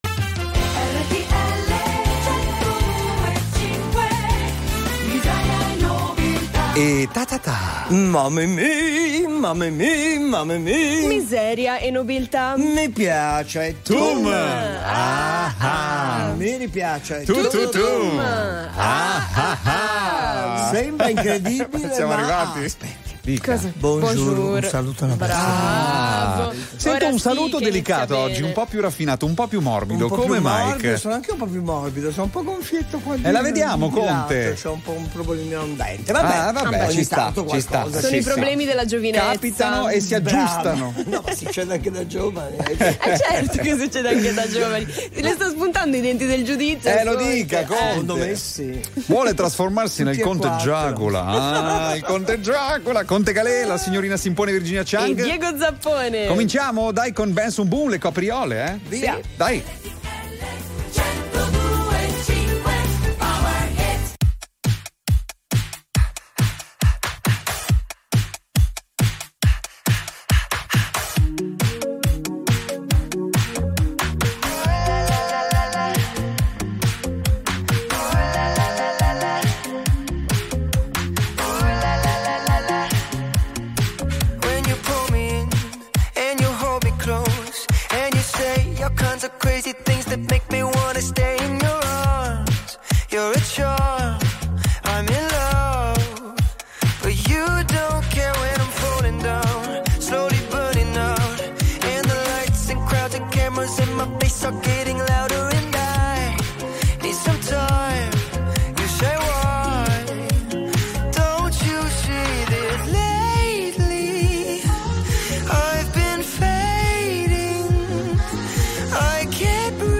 Il programma si ascolta su RTL 102.5, in RADIOVISIONE sul canale 36 del Digitale Terrestre e sul canale 736 di Sky, e in streaming su RTL 102.5 PLAY.
Due ore di intrattenimento e bella musica.